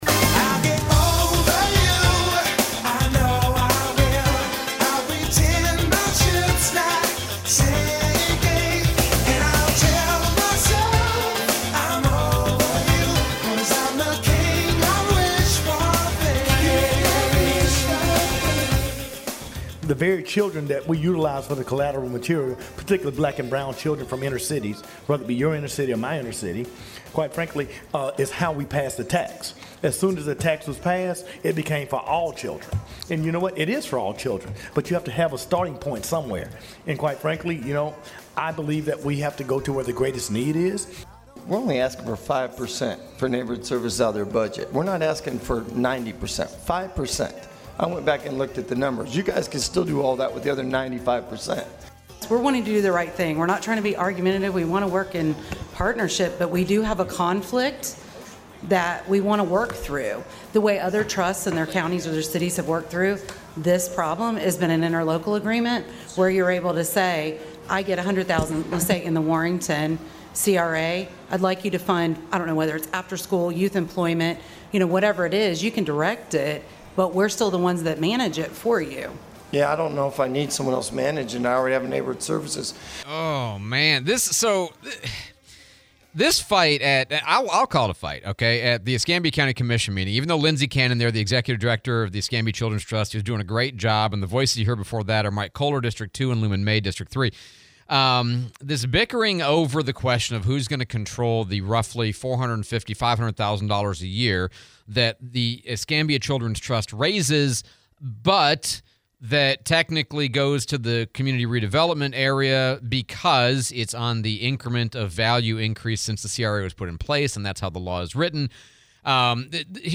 Escambia Children's Trust and interlocal agreements / Interview with Sheriff Chip Simmons